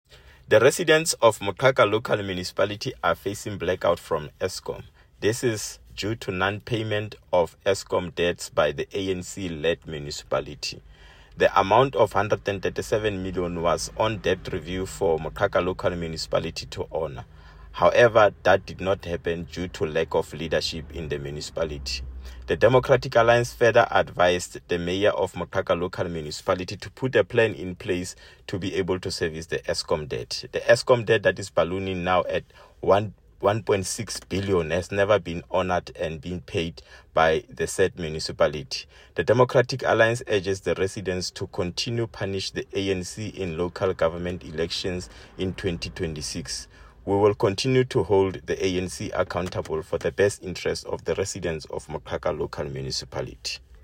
Sesotho soundbites by Cllr David Nzunga.